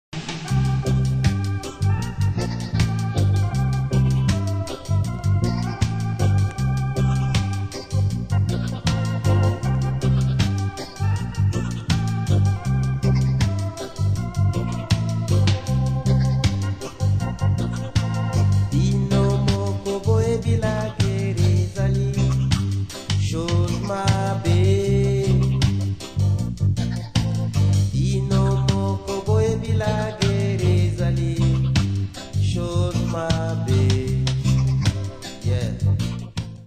přední představitelé českého reggae